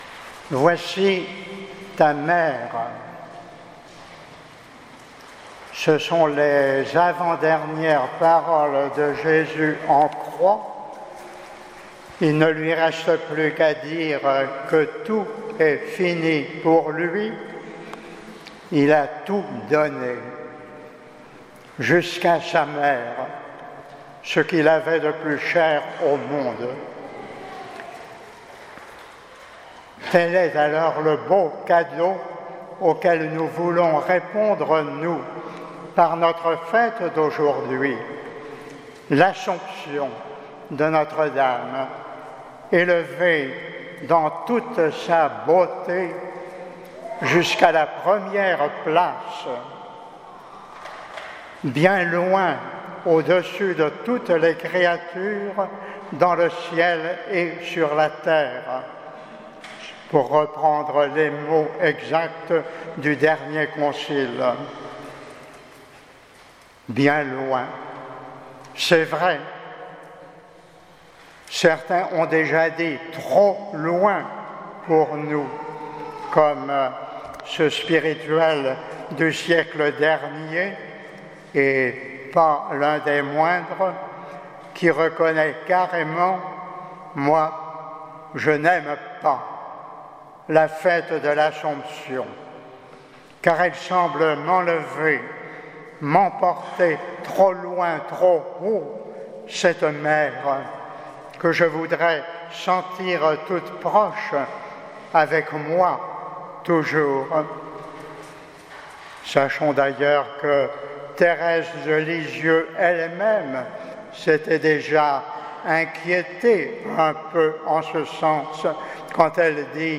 Homélie de la Solennité de l’Assomption 2018